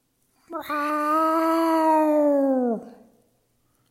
meows-2.mp3